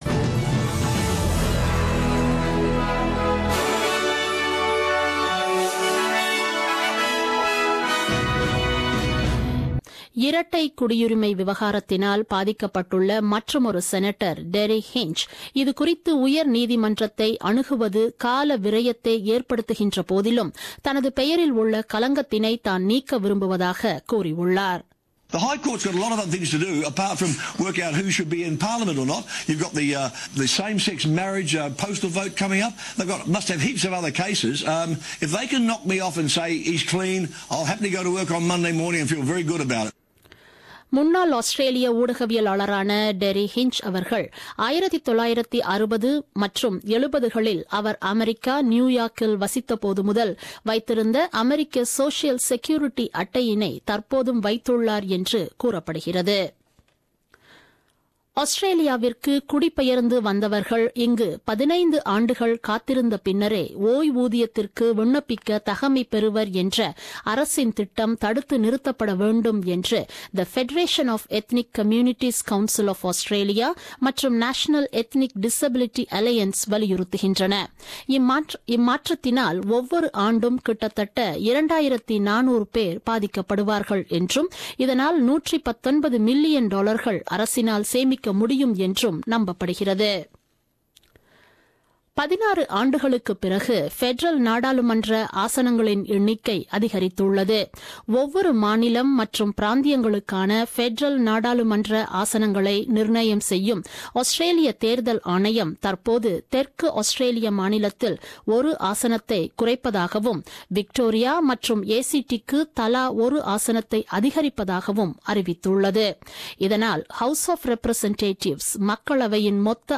The news bulletin broadcasted on 1st September 2017 at 8pm.